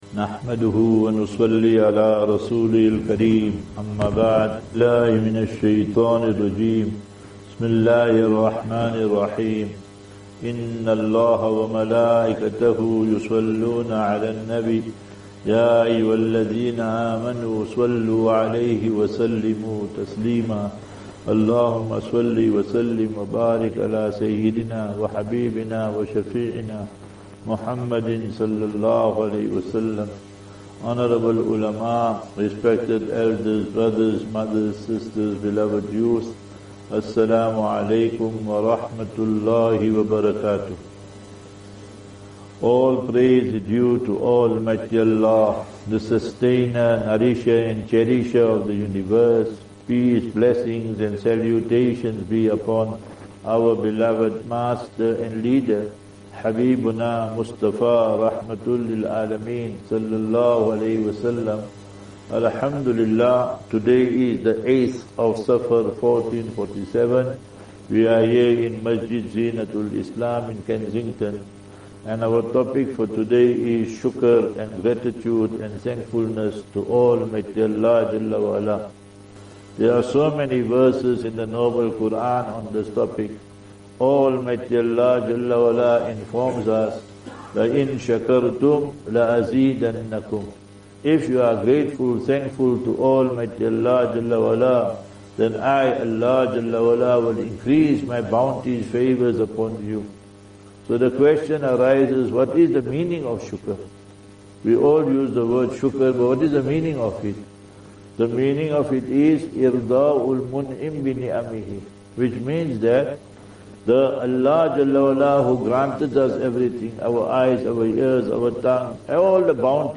3 Aug 03 August 2025 -Lecture at Masjid Zeenatul-Islam, Kensington
Lectures